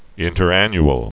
(ĭntər-ăny-əl)